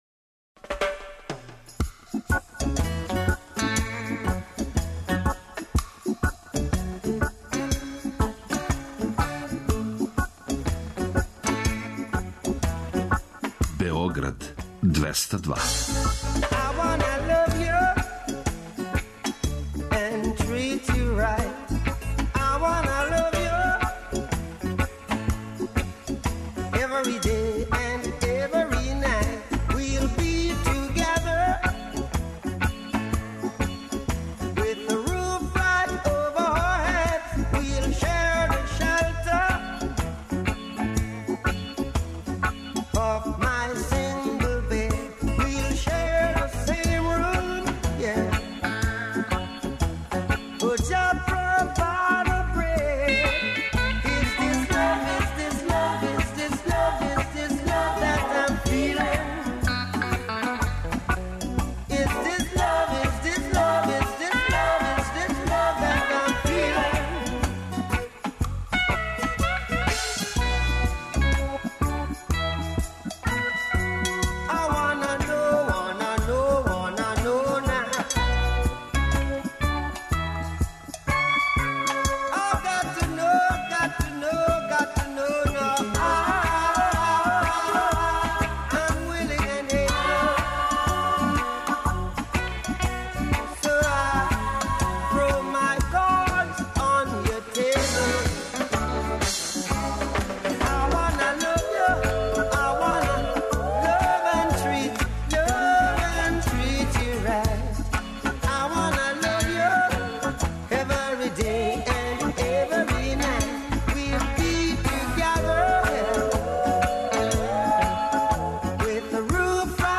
Клинцијада 202 уживо из Тутина. Упознајте и дружите се са најмлађим становницима Тутина и Двестадвојком испред Куће Мира у четвртак 18. јуна од 14.00 до 15.00 сати.